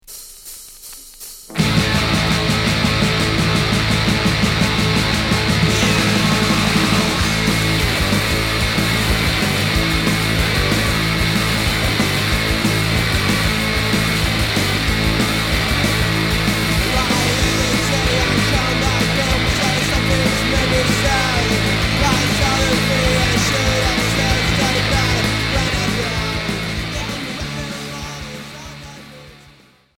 Rock noisy